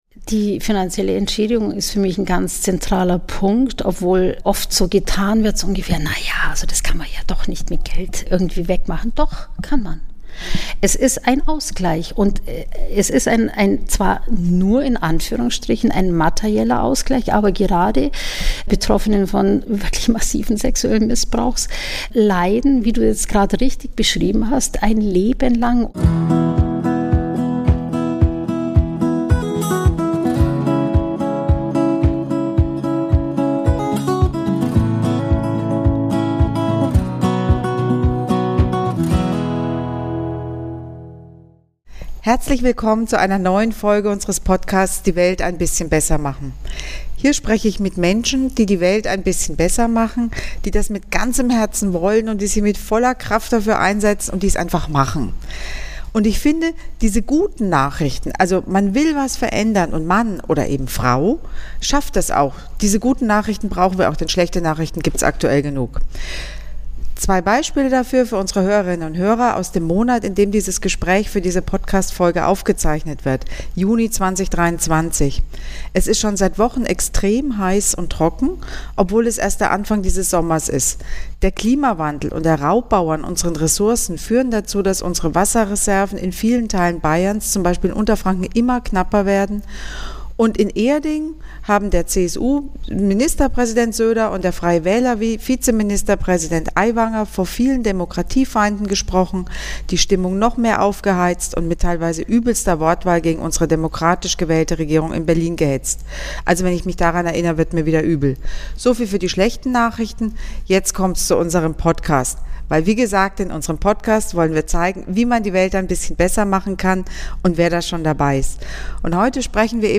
Im Gespräch mit Moderatorin und Landtagskollegin Kerstin Celina erzählt sie von diesem Teil ihrer Arbeit, was sie antreibt und wo es politisch hakt.